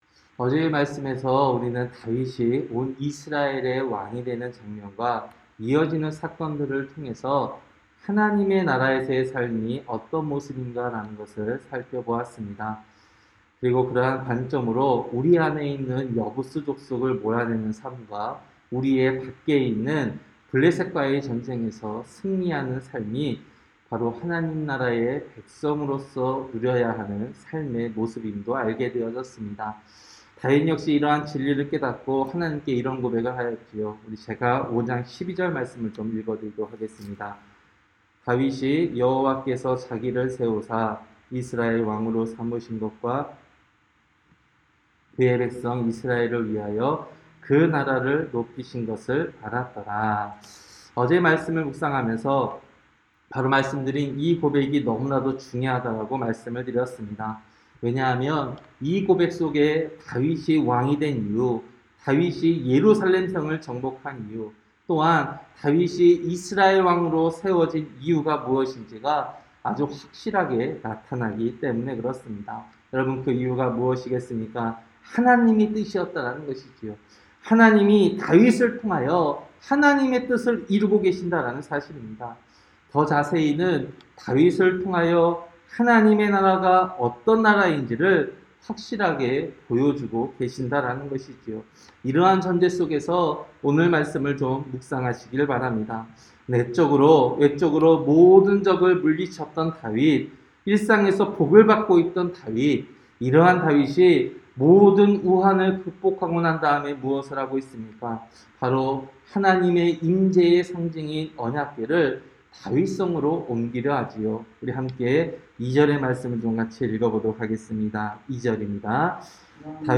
새벽설교-사무엘하 6장